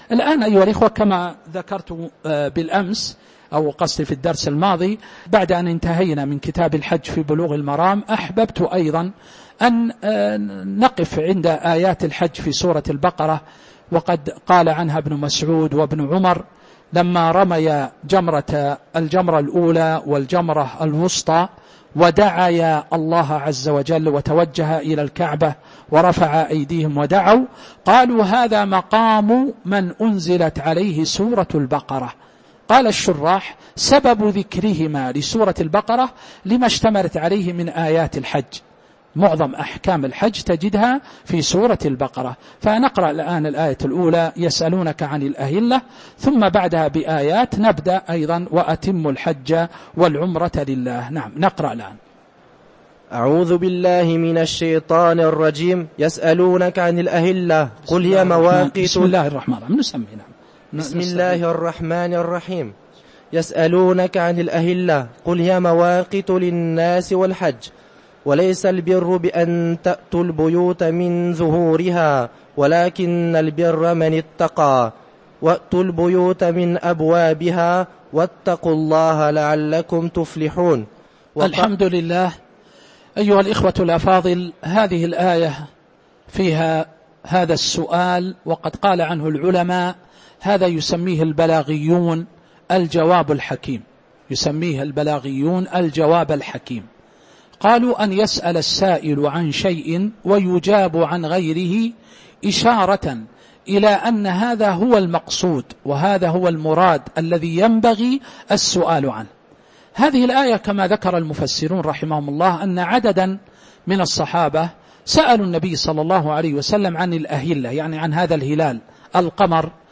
تاريخ النشر ٧ ذو الحجة ١٤٤٥ هـ المكان: المسجد النبوي الشيخ